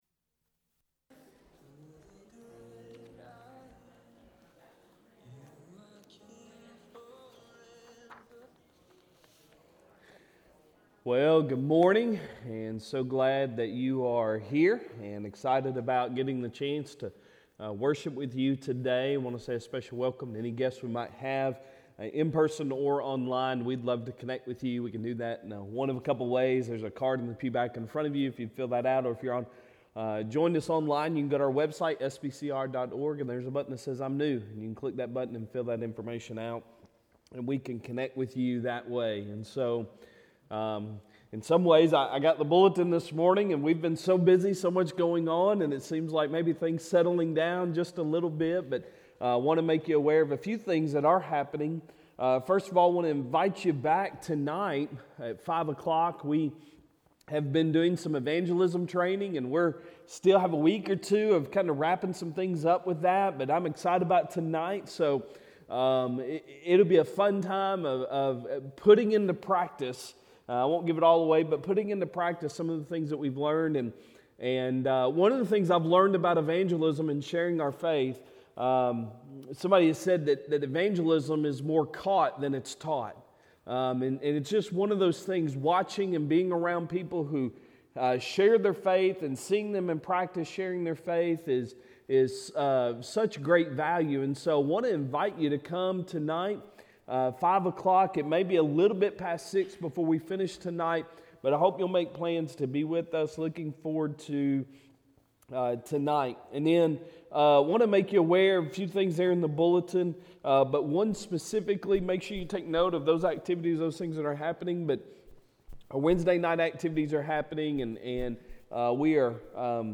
Sunday Sermon April 16, 2023